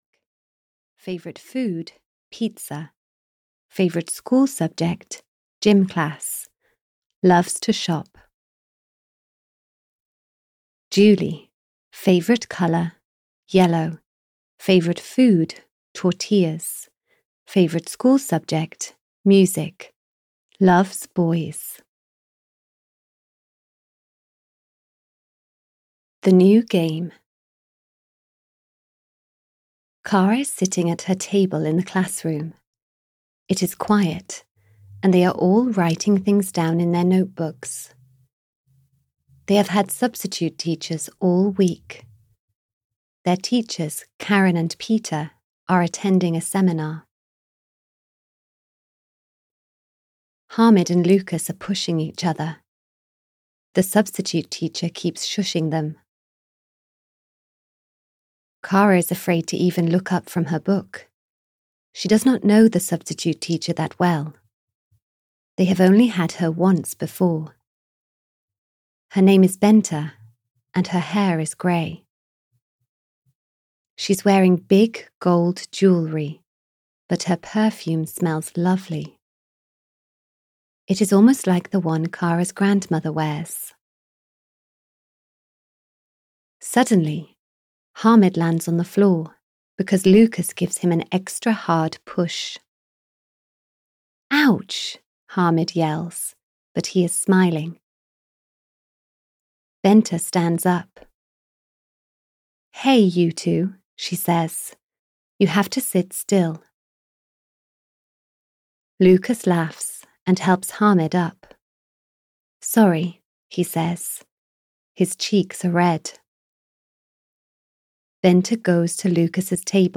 Audio knihaK for Kara 17 - Just a Joke! (EN)
Ukázka z knihy